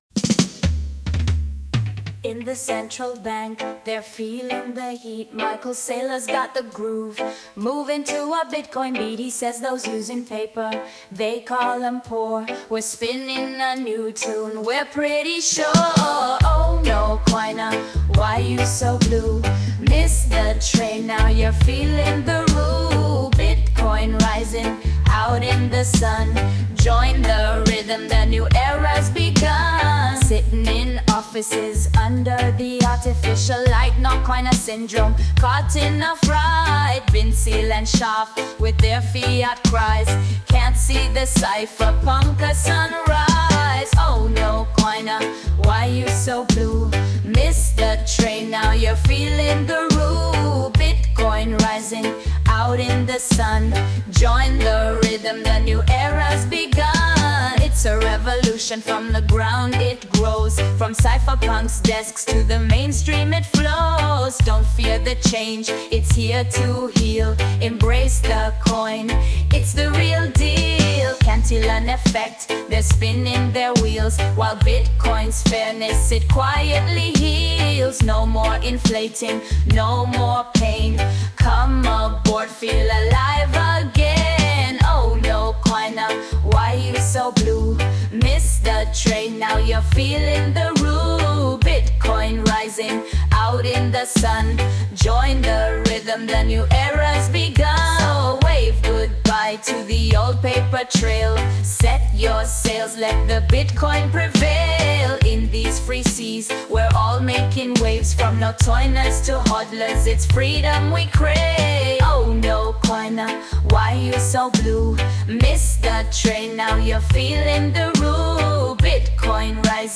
in reggae style!